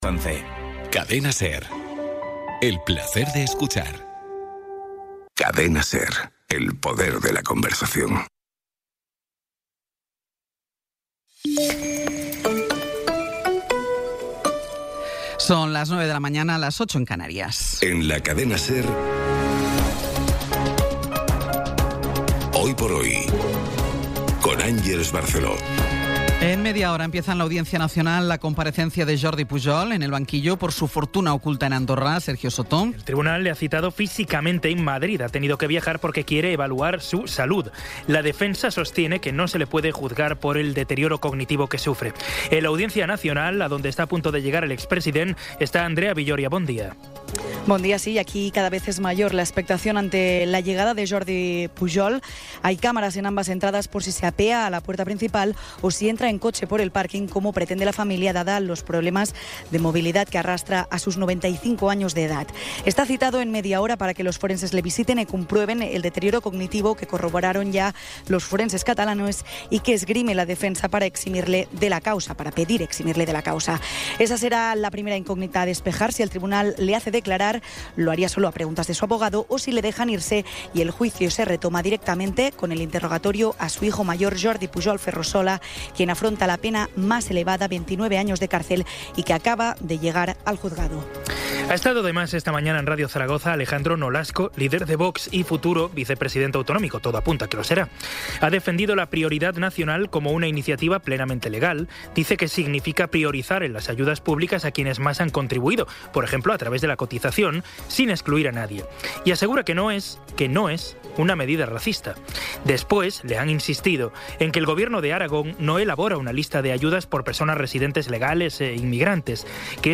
Resumen informativo con las noticias más destacadas del 27 de abril de 2026 a las nueve de la mañana.